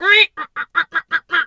duck_2_quack_seq_02.wav